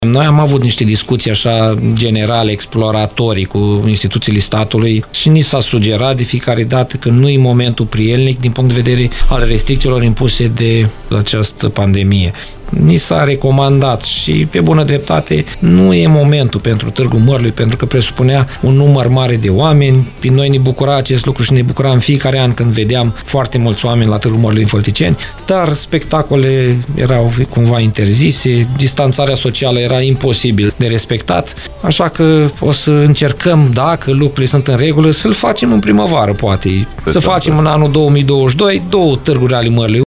Primarul CĂTĂLIN COMAN a declarat postului nostru de radio că – în cazul în care anul viitor situația va reveni la normal – municipalitatea studiază posibilitatea organizării a 2 Tâguri ale Mărului, unul în primăvară și altul în toamnă.